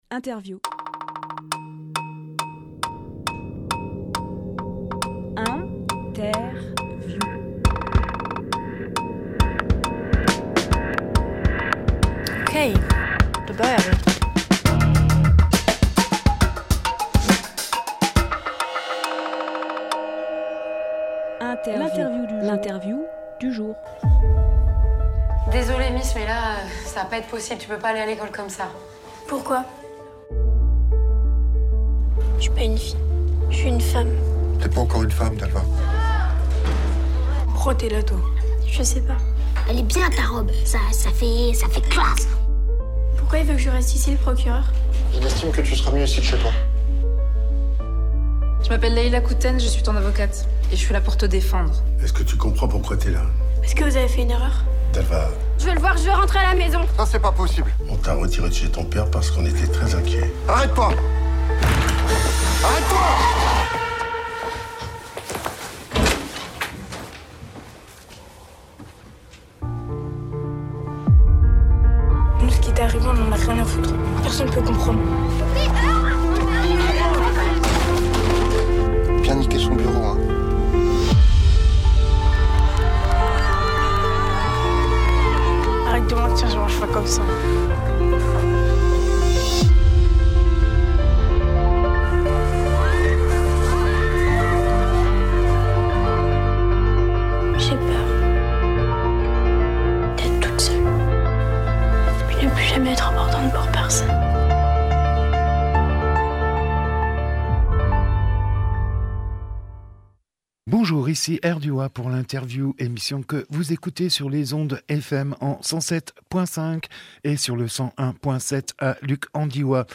Emission - Interview
14.04.23 Lieu : Studio RDWA Durée